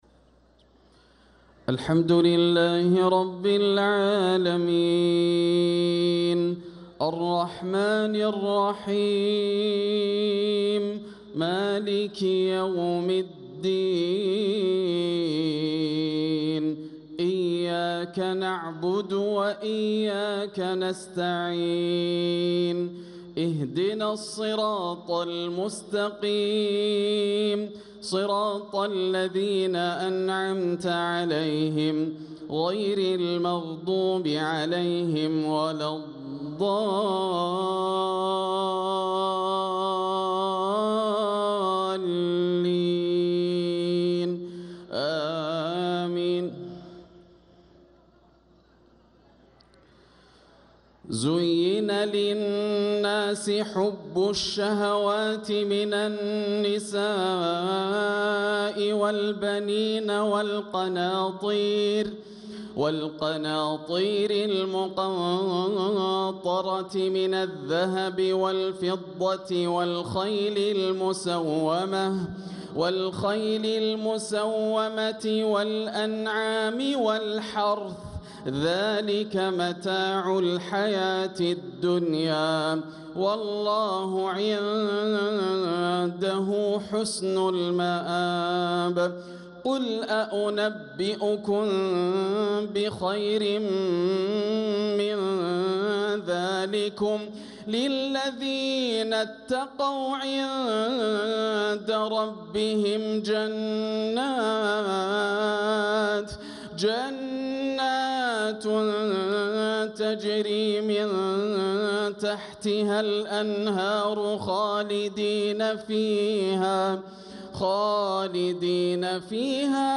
صلاة العشاء للقارئ ياسر الدوسري 18 رجب 1446 هـ